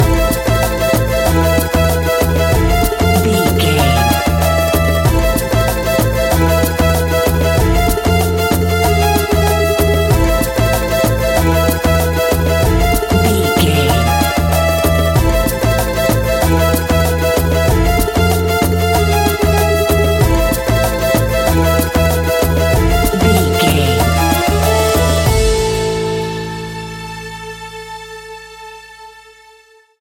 Aeolian/Minor
World Music
percussion
congas
bongos
djembe
marimba